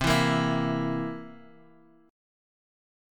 C7 Chord
Listen to C7 strummed